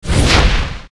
menuhit2.ogg